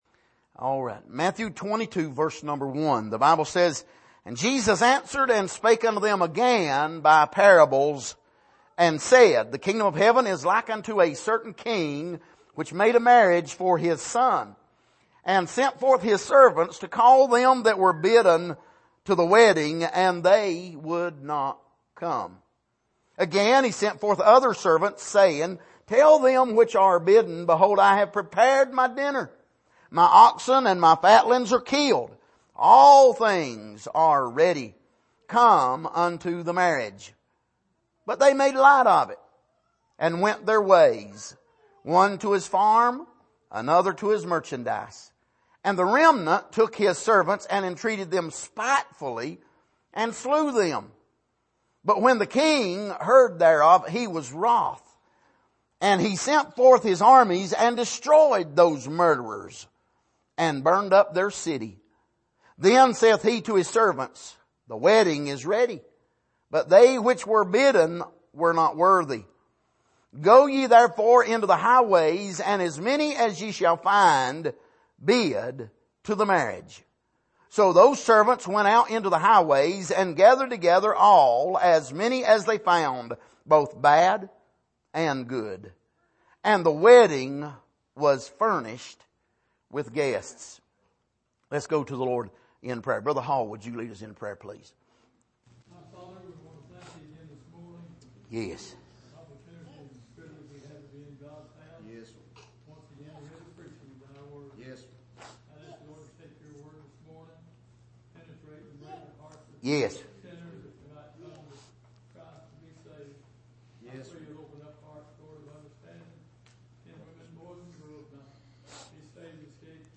Miscellaneous Passage: Matthew 22:1-10 Service: Sunday Morning